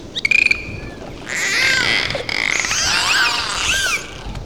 01_dauphin.mp3